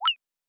Digital Click 11.wav